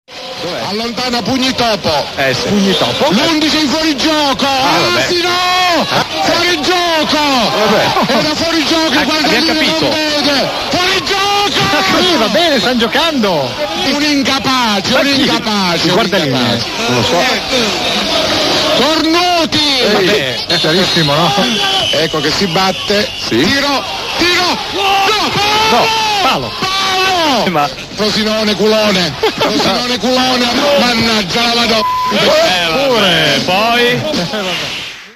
l' MP3, tratto da "Mai Dire Gol" di qualche anno fa, della partita di calcio Giulianova-Frosinone, con un commentatore giuliese quantomeno eccentrico ("....